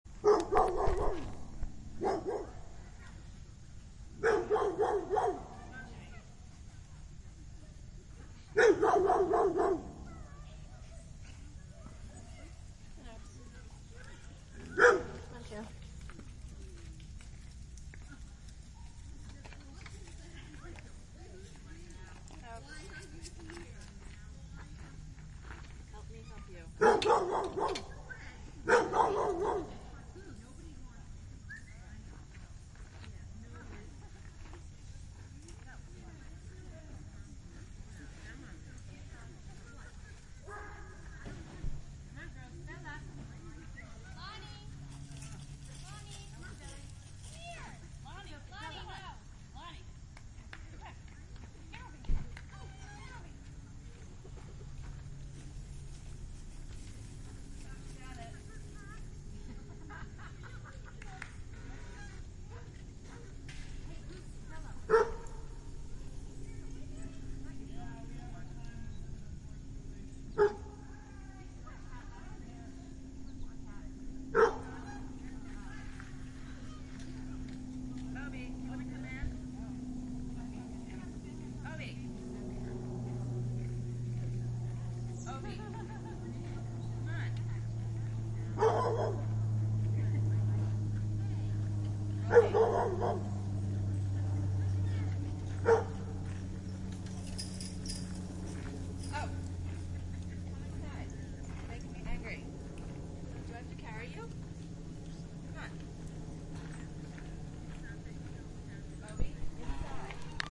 013491_barking Dogs At Dog Park Sound Button - Free Download & Play